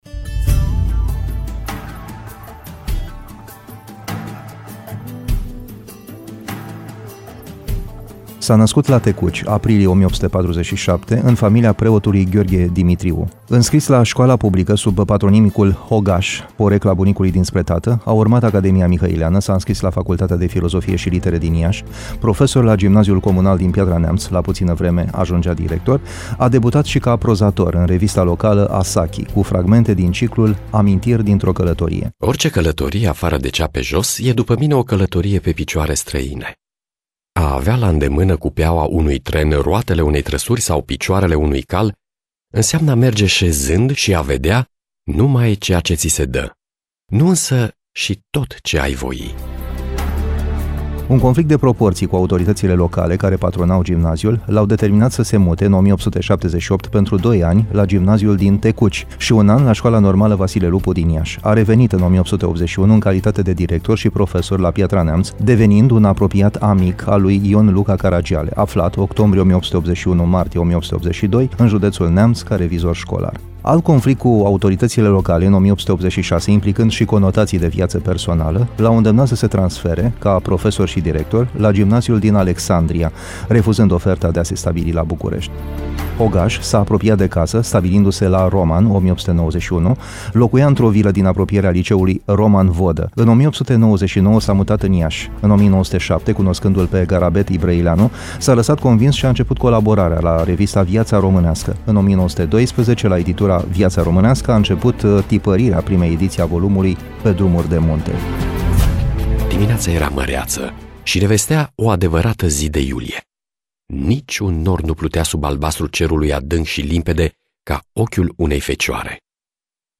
a folosit fragmente cuprinse în audiobook Amintiri dintr-o călătorie